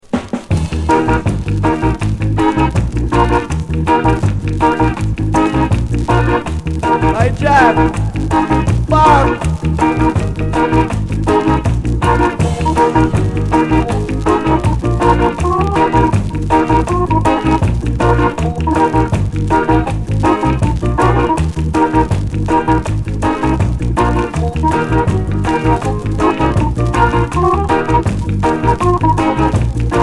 Notes: bit noisy pressing